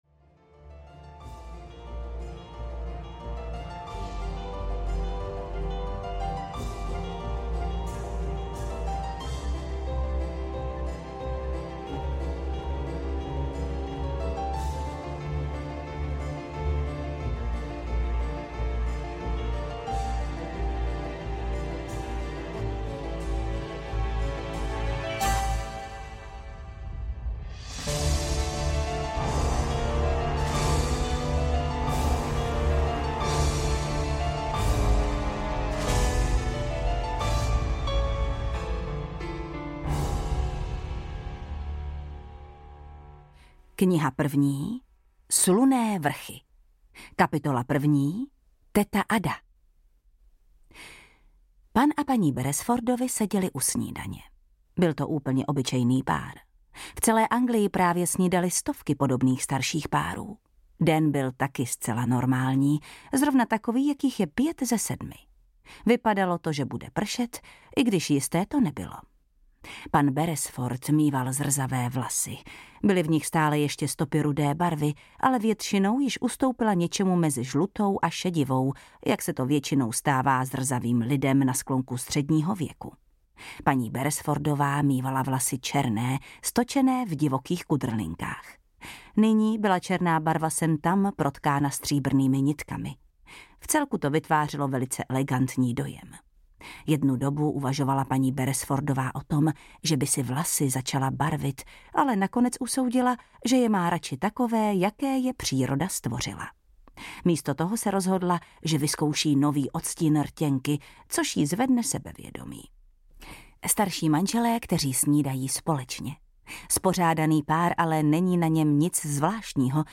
Dům u kanálu audiokniha
Ukázka z knihy
• InterpretJana Stryková